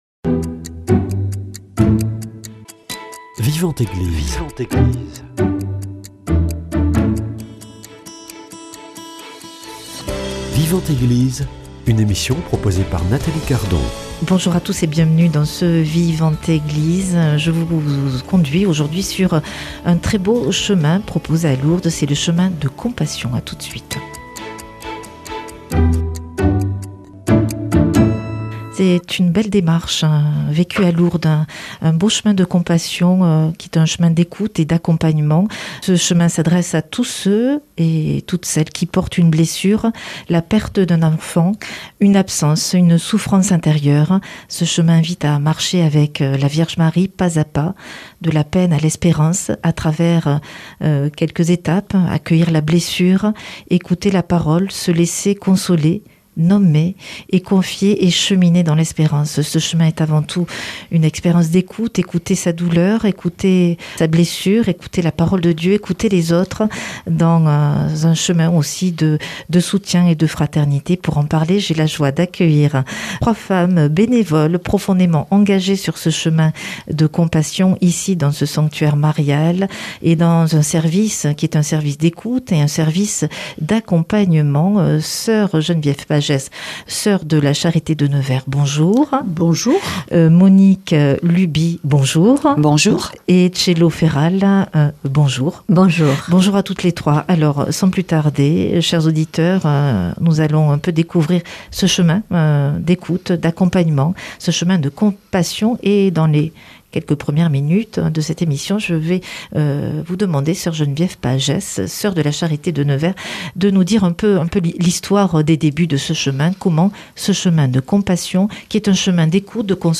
trois femmes engagées dans ce service d’écoute et d’accompagnement à Lourdes